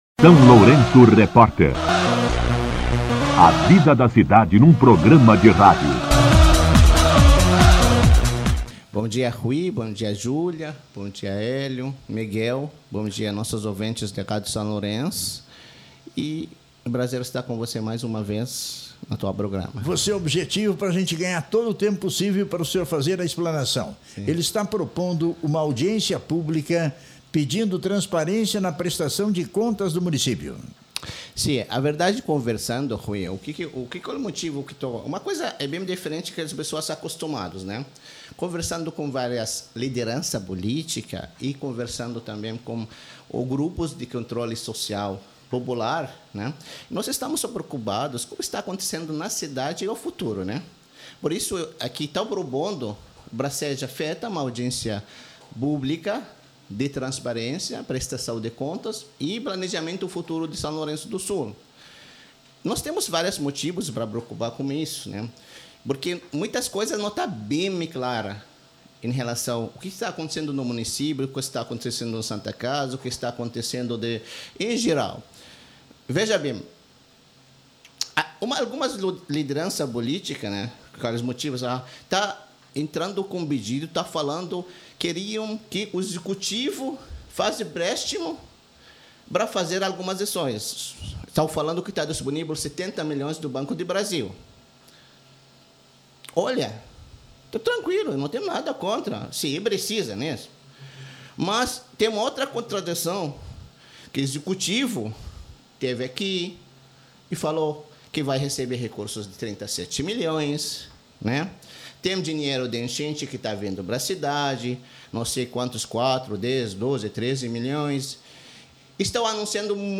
Entrevista com O empresário